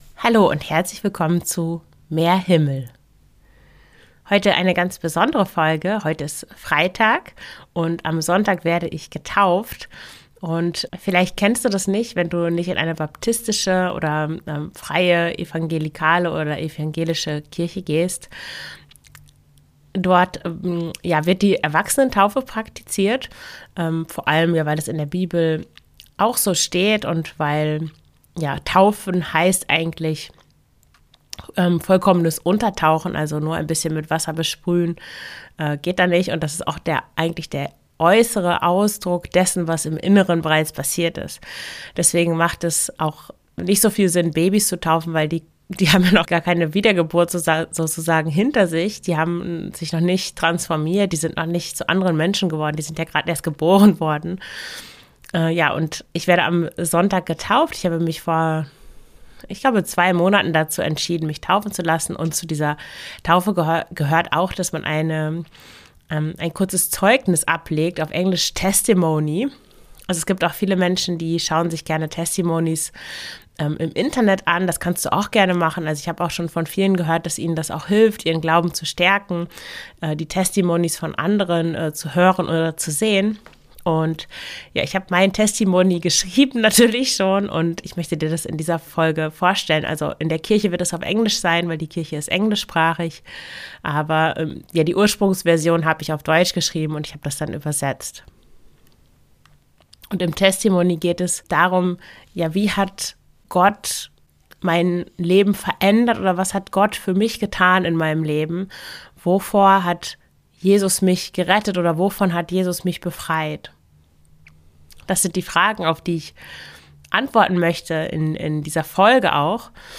Wie hat Glaube mein Leben verändert? (christliches Glaubenszeugnis) ~ Mehr Himmel – Mach mich ganz.